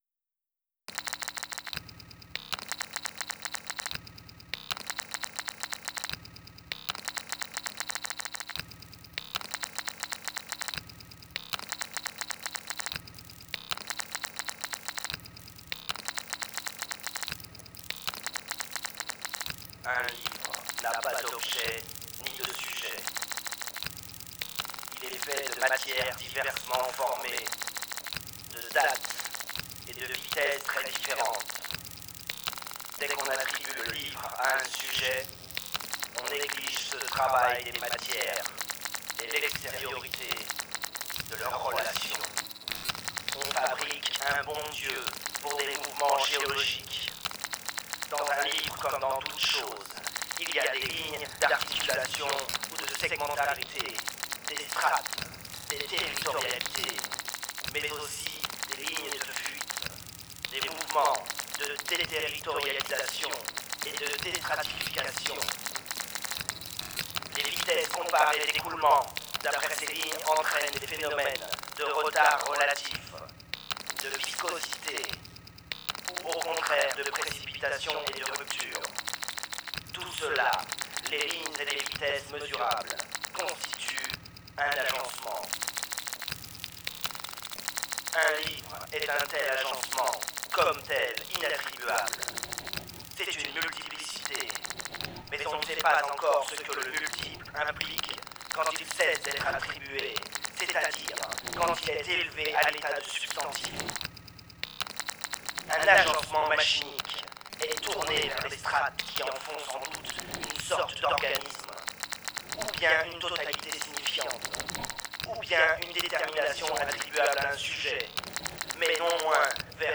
création sonore à partir d’un extrait de Mille plateaux
Deleuze et Guattari machinés par le poète acousmatique.